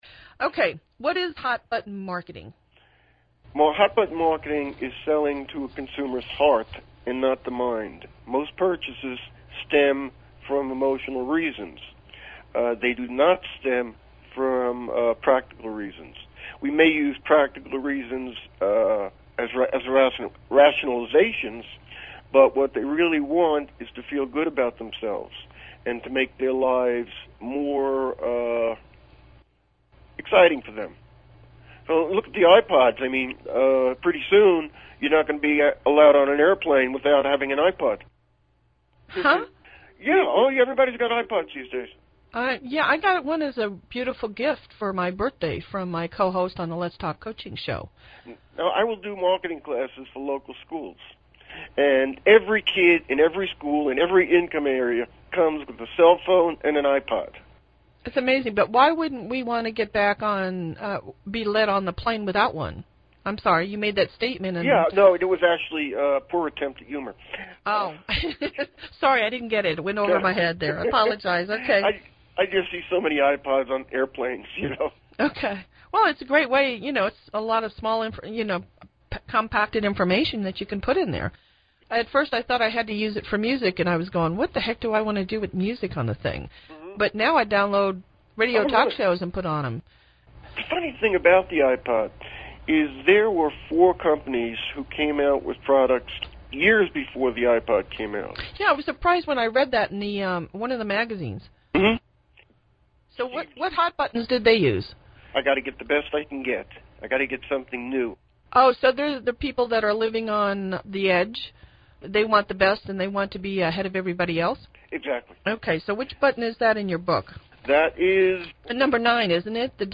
other radio interview.mp3